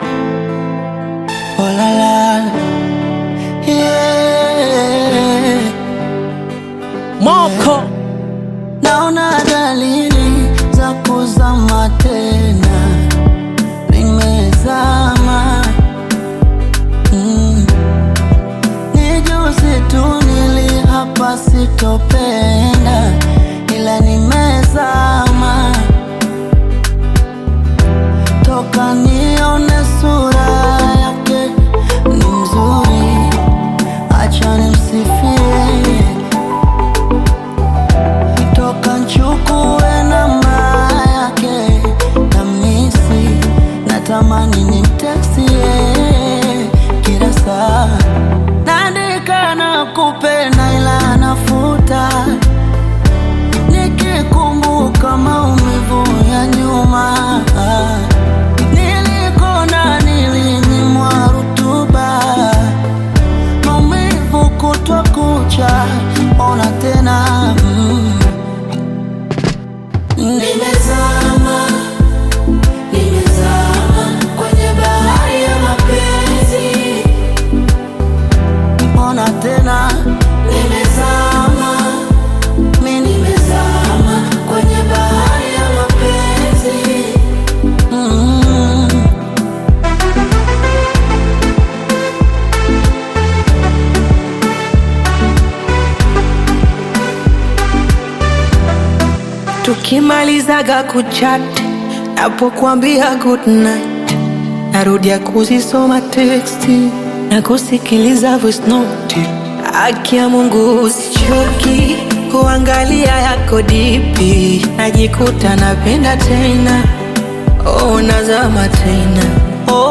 Tanzanian Bongo Flava artists
Bongo Flava You may also like